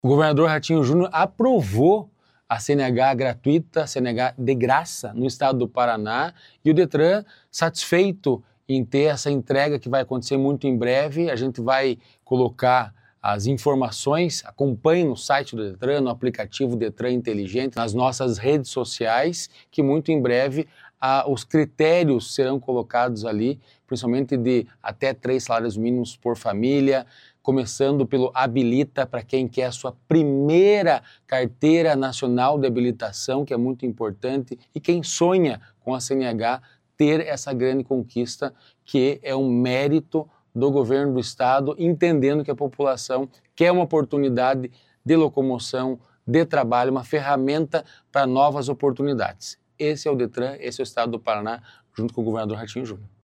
Sonora do diretor-presidente do Detran-PR, Santin Roveda, sobre a lei que prevê CNH gratuita para pessoas em vulnerabilidade social